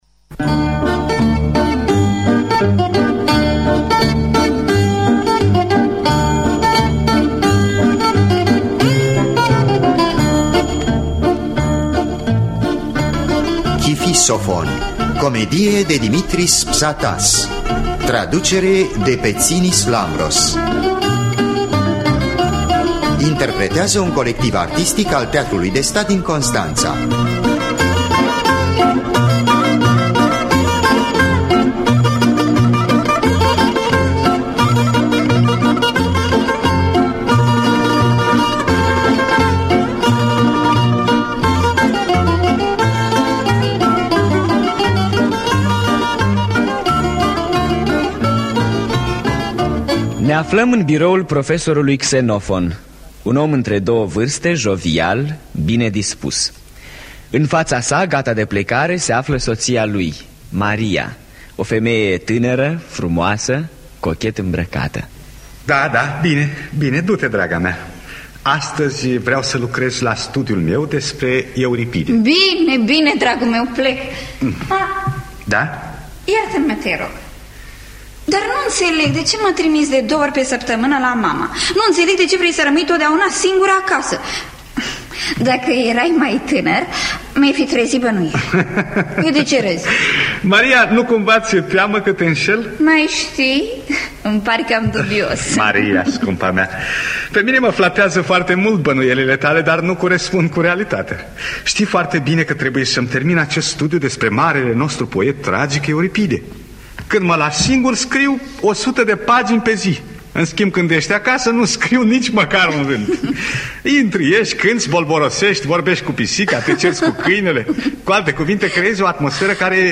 Adaptarea radiofonică
Interpretează actori ai Teatrului de Stat din Constanţa.